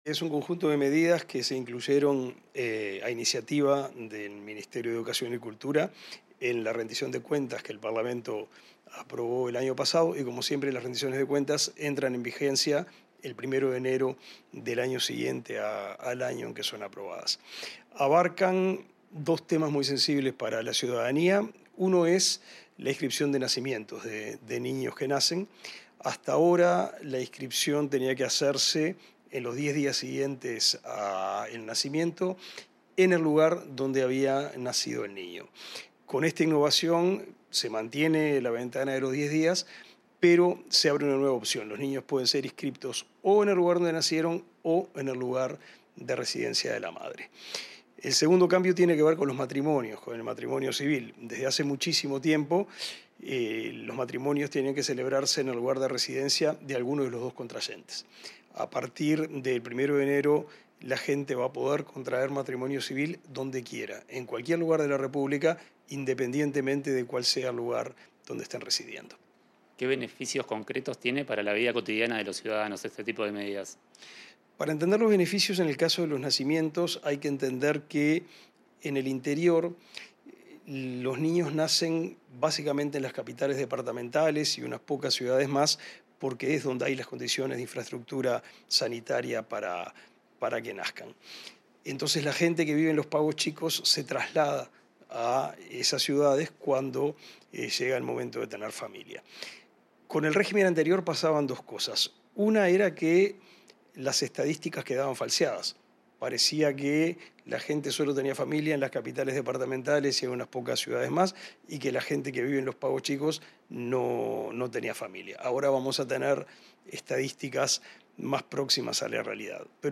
Entrevista al ministro de Educación y Cultura, Pablo da Silveira
El ministro de Educación y Cultura, Pablo da Silveira, dialogó con Comunicación Presidencial sobre los cambios en los trámites de inscripción de